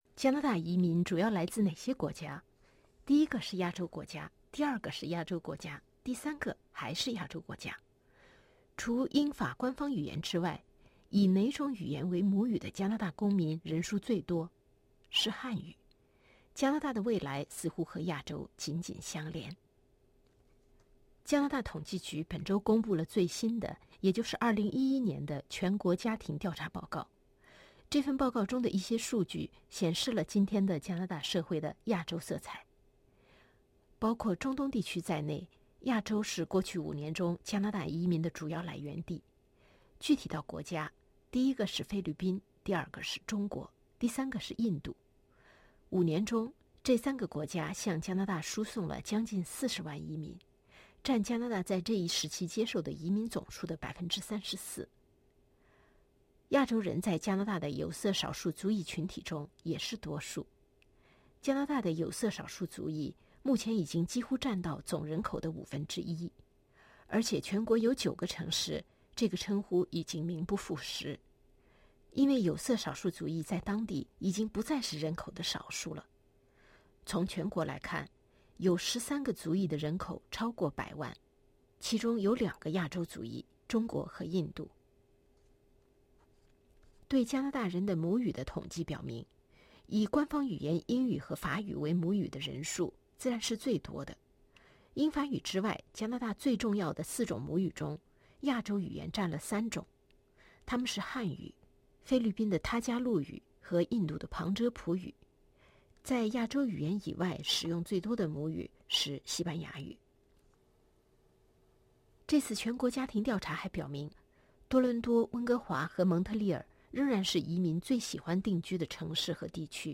by | posted in: 报道 | 0